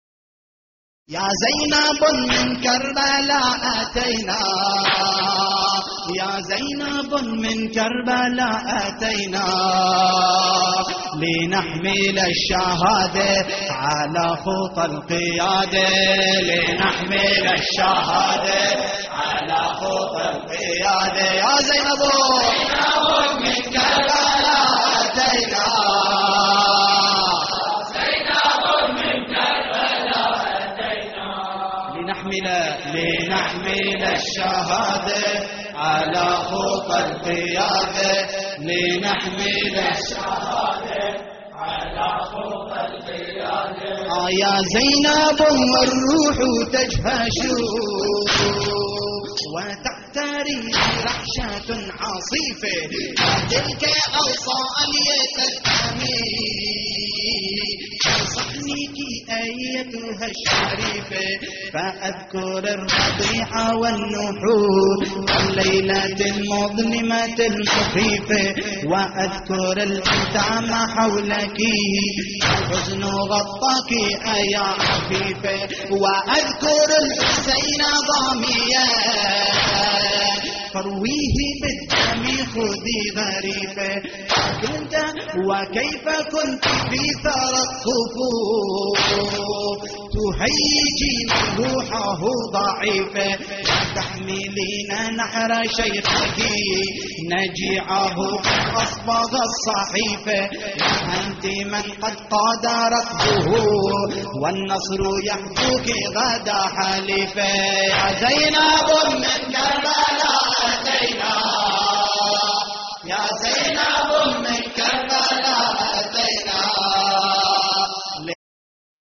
تحميل : يا زينب من كربلاء اتينا / مجموعة من الرواديد / اللطميات الحسينية / موقع يا حسين